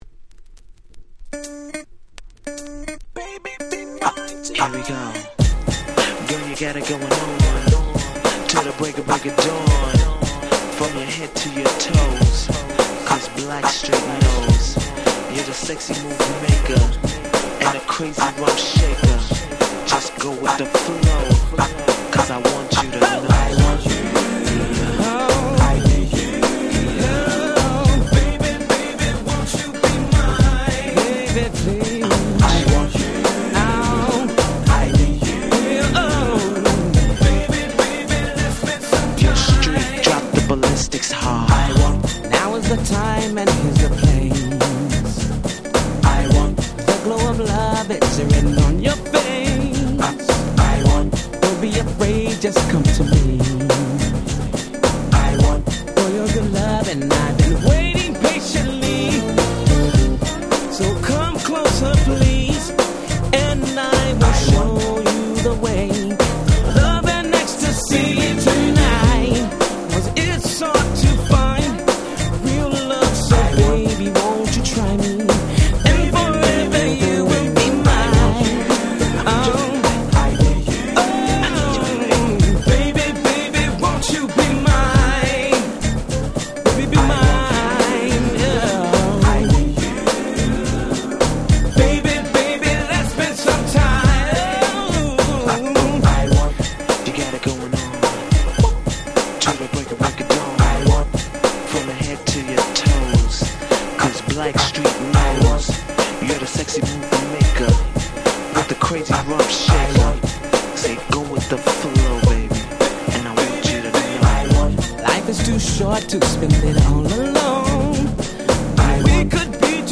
93' Big Hit R&B !!
バッキバキにハネてます！
(Vocal)